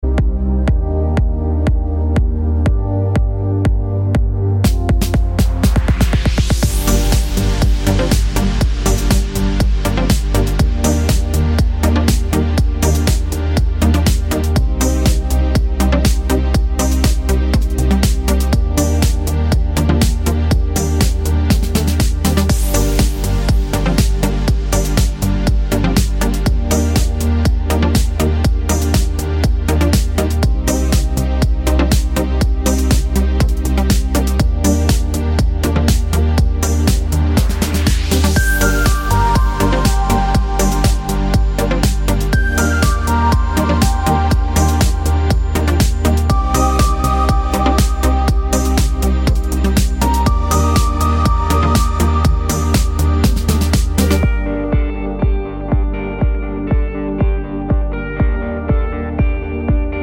no Backing Vocals Finnish 3:30 Buy £1.50